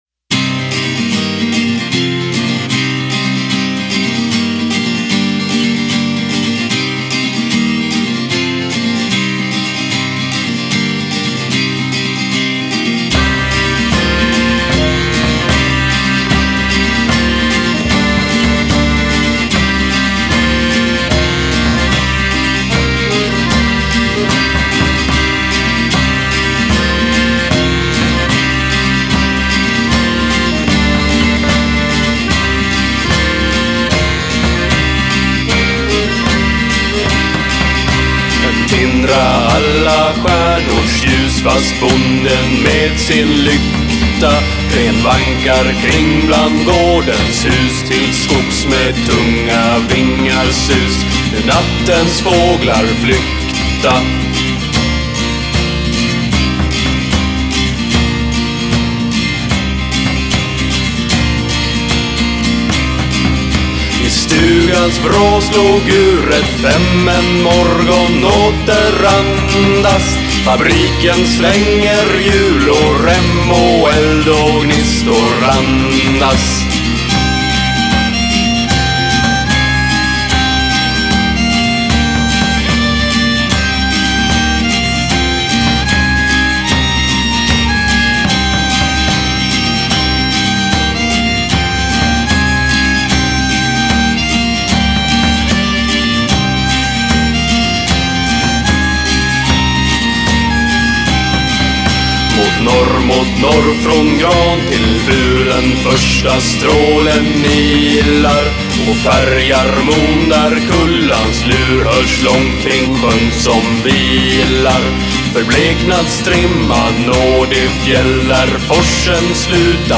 Style: Neofolk